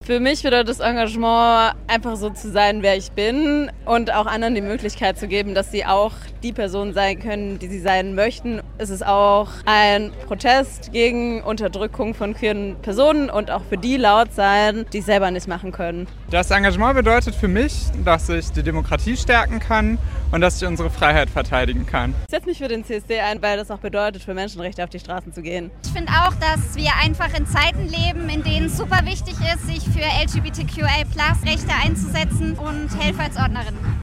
Warum die Organisatorinnen und Organisatoren sich für den CSD engagieren: